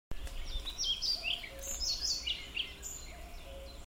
мухоловка-пеструшка, Ficedula hypoleuca
Administratīvā teritorijaValmiera
СтатусПоёт